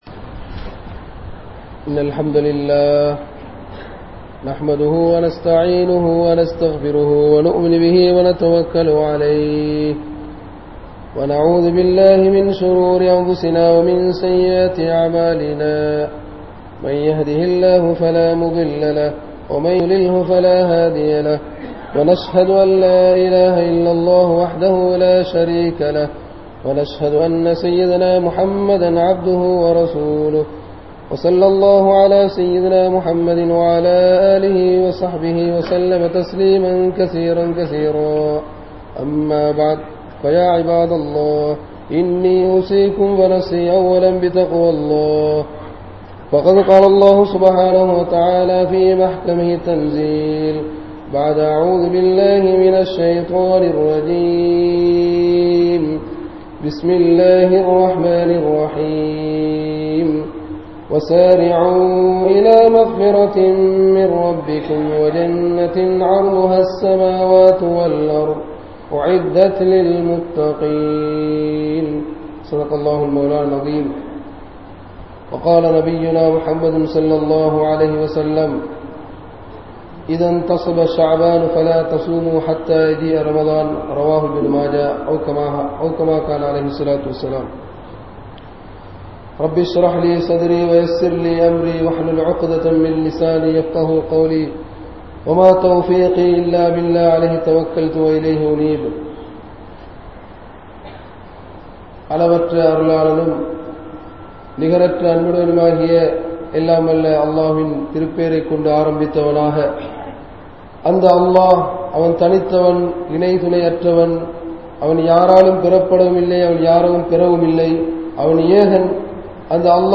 Shabaan Maathathin Sirappuhal | Audio Bayans | All Ceylon Muslim Youth Community | Addalaichenai
Grand Jumua Masjith